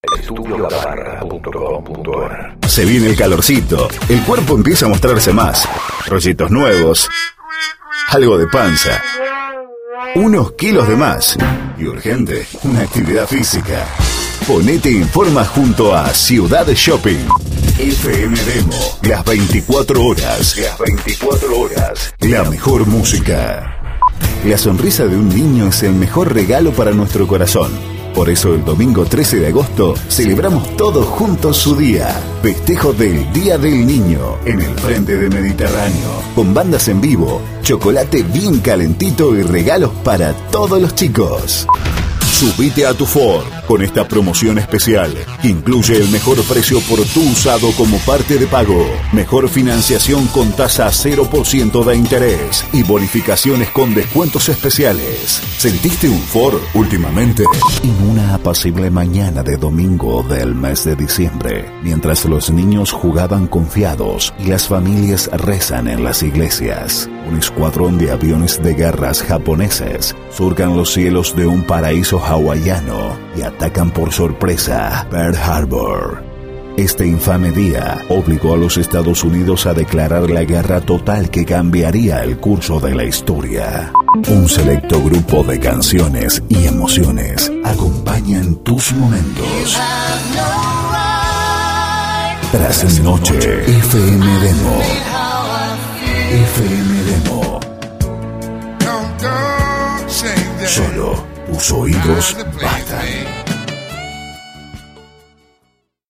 Explora la versatilidad vocal de nuestros locutores, expertos en locución dulce, enérgica, institucional, narrativa, promocional y sensual.
Castellano (acento rioplatense)
Voz versátil para todo tipo de grabaciones. DEMO GENERAL: